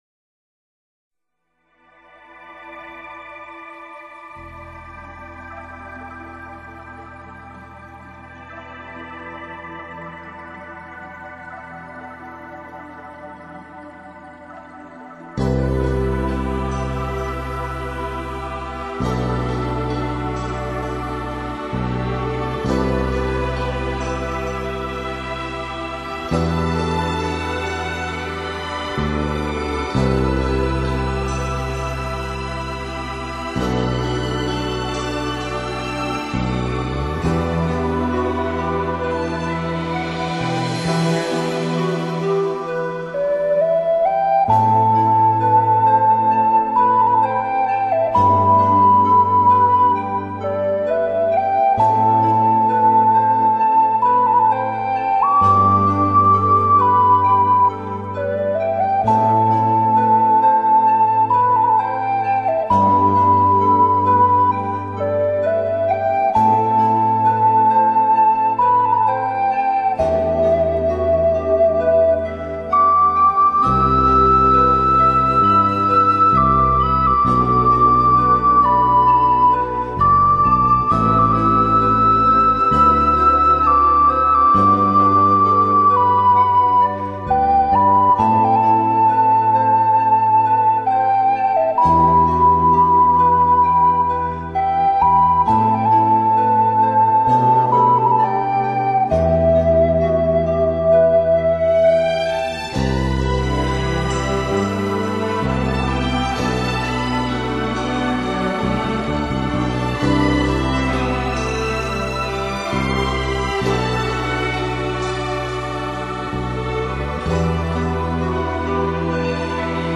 这是日本的陶笛